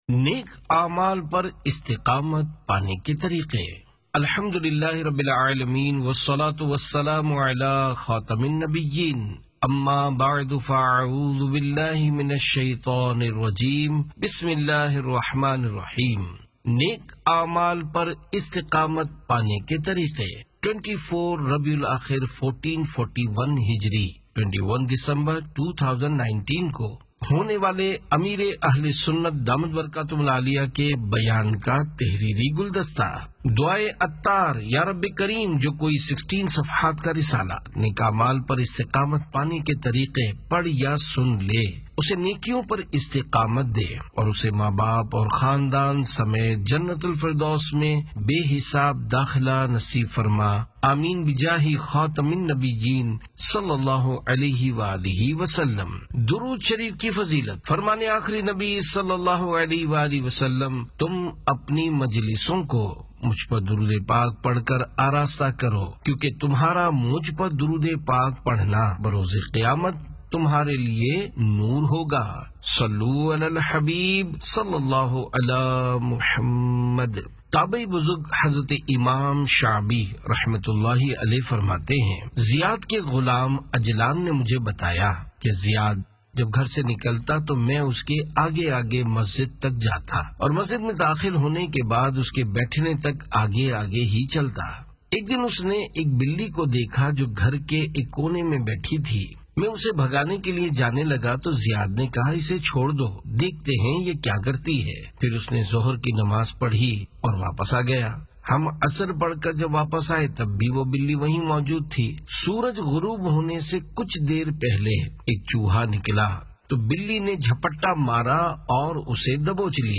No Media Found Audiobook